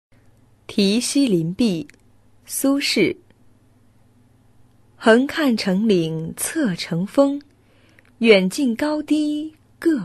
五年级语文下册课文朗读 题西林壁 3（语文a版）_21世纪教育网-二一教育